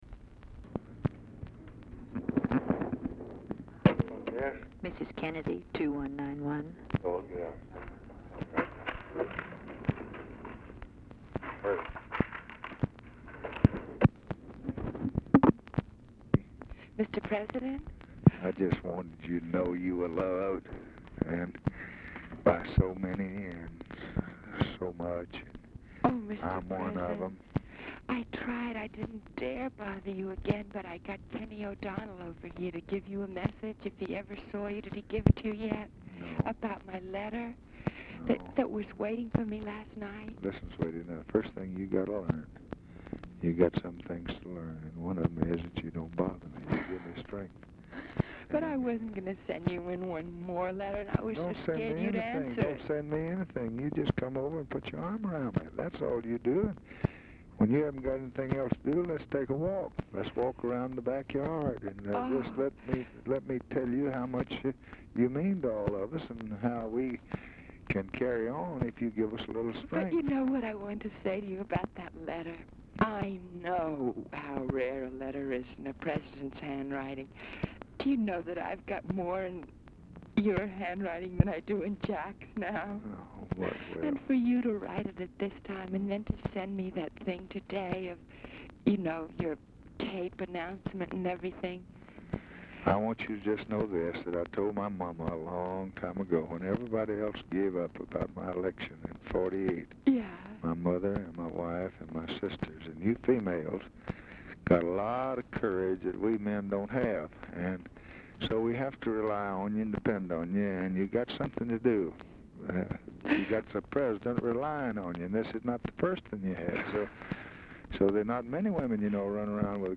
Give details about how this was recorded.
Oval Office or unknown location Dictation belt